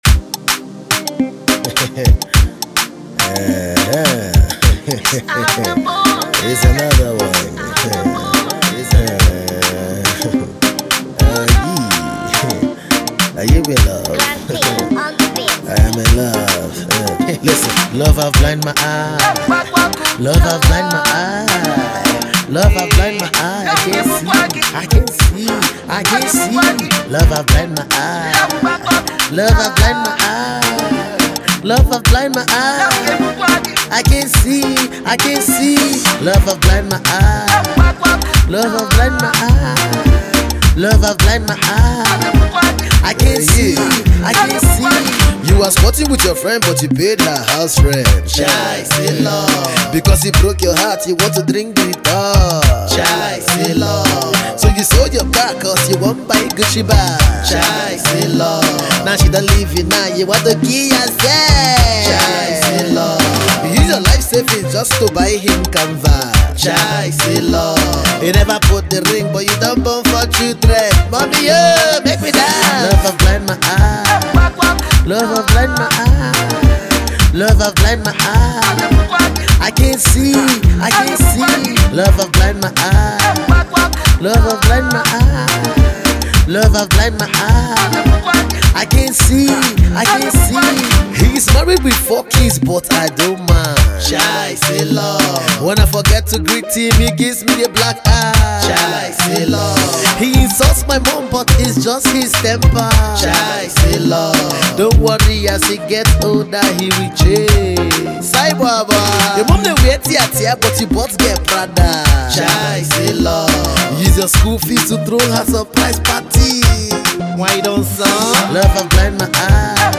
funny single
comic singles